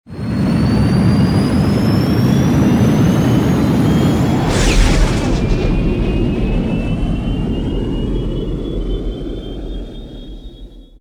LaunchFromDockingBay.wav